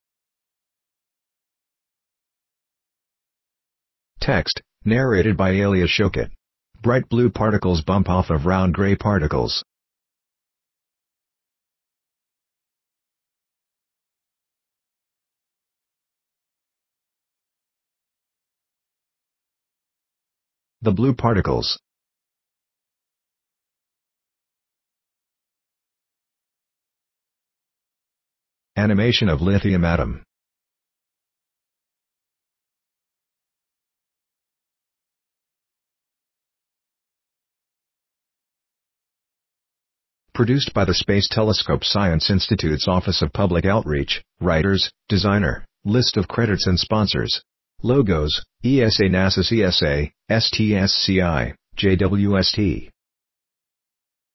• Audio Description
Audio Description.mp3